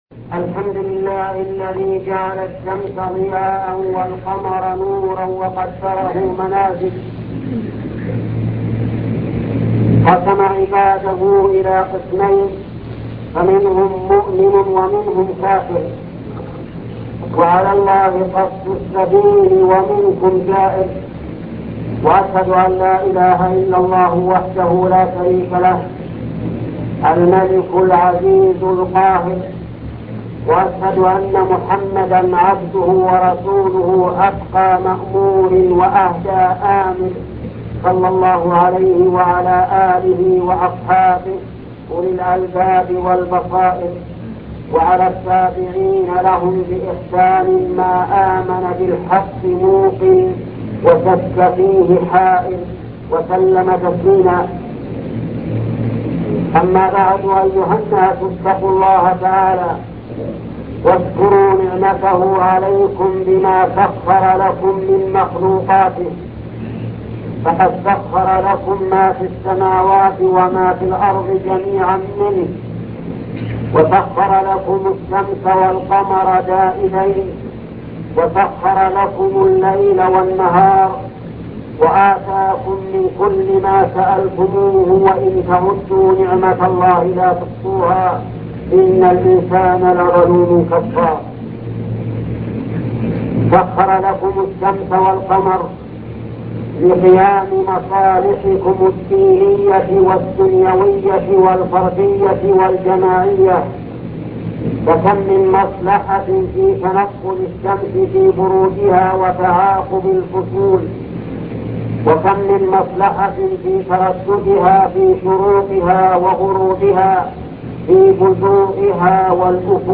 خطبة وجوب أداء صلاة الكسوف - صفة صلاة الكسوف الشيخ محمد بن صالح العثيمين